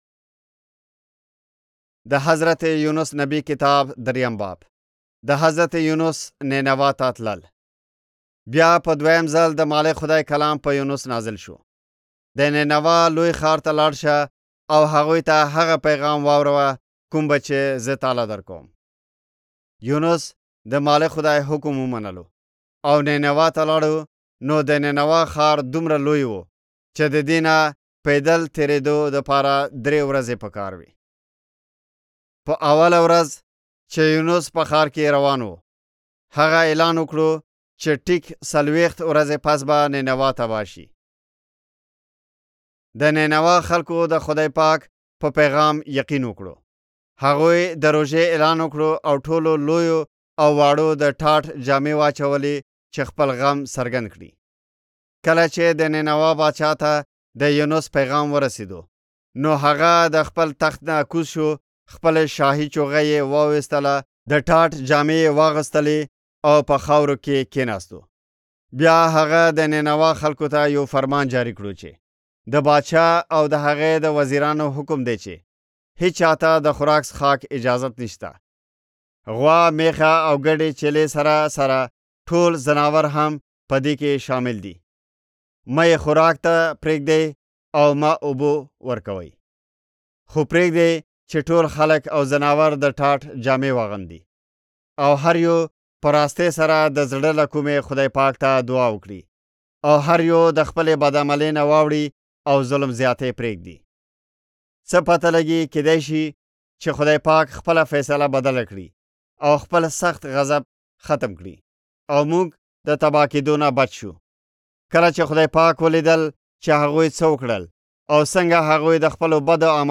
Jonah Audiobook (YZ) – Pashto Zeray
يوسفزئ ختیځ افغانستان ختیځ افغانستان